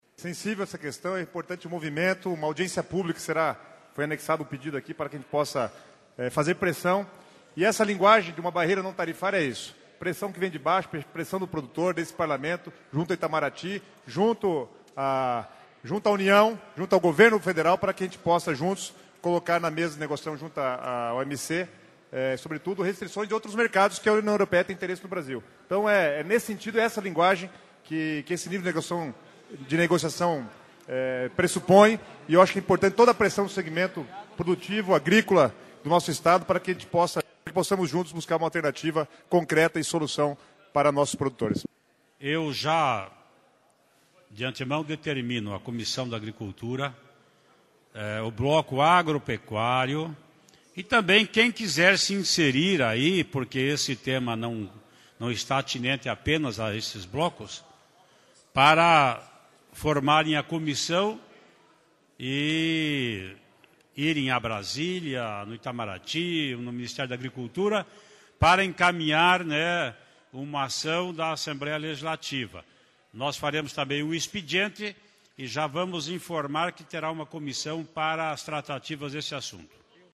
Assembleia aprova Comissão Especial para acompanhar embargo da União Europeia à carne de frango brasileria e paranaense. vamos ouvir o que disseram os deputados Guto Silva (PSD) e o rpesidente Ademar Traiano (PSDB) sobre o tema.